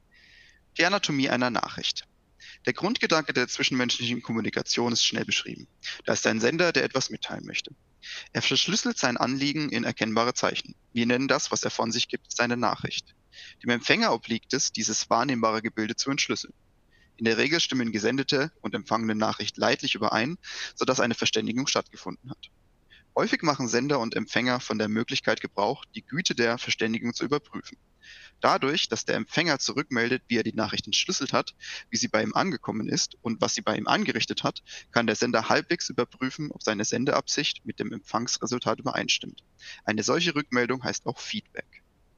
Um einen Eindruck von der Mikrofonqualität zu bekommen, wurde ein kurzer Ausschnitt aus dem Buch "Miteinander reden: 1 - Störungen und Klärungen, Allgemeine Psychologie der Kommunikation" von Friedemann Schulz von Thun (erschienen im Rowohlt Taschenbuch Verlag) vorgelesen und aufgenommen.
Videokonferenzaufnahmen